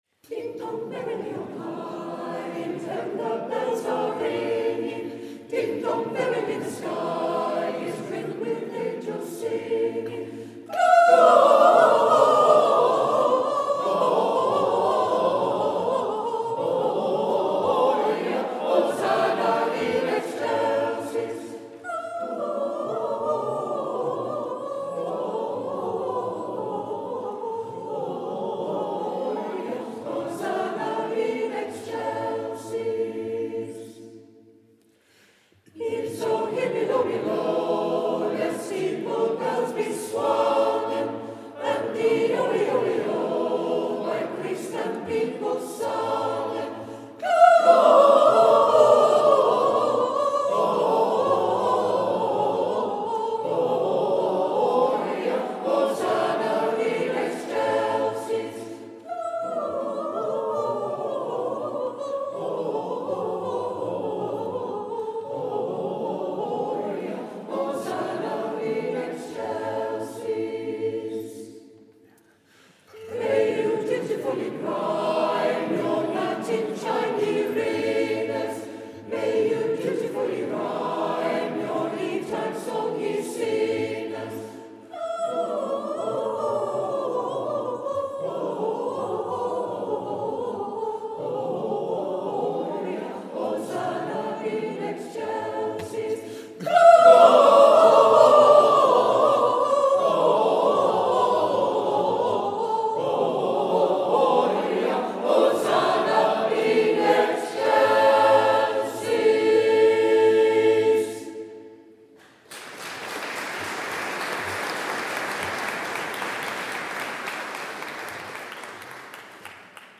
Holy Trinity Church in Minchinhampton looked wonderfully festive for our Christmas concert this year.
The audience did a fine job of joining in with a number of favourite carols too.
organ